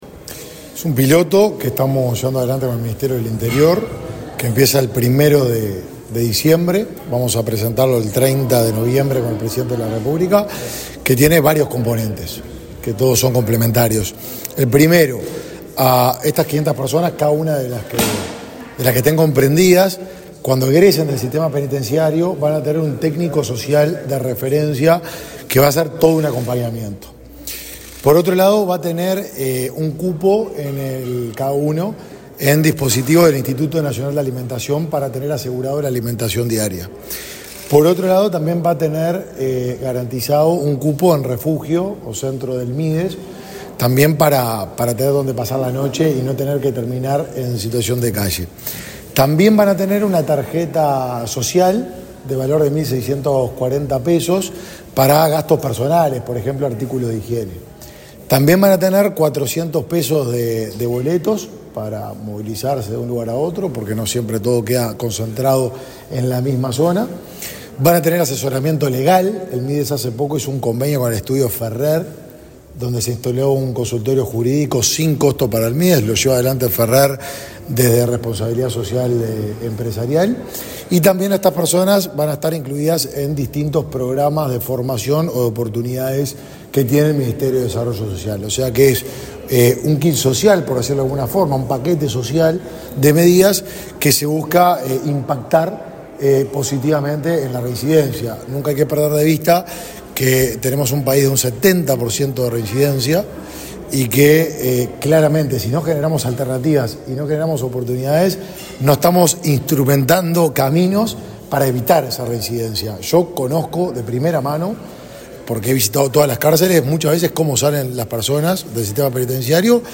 Declaraciones del ministro de Desarrollo Social, Martín Lema
El ministro de Desarrollo Social, Martín Lema, dialogó con la prensa, luego de participar, este viernes 17 en Torre Ejecutiva, de un encuentro sobre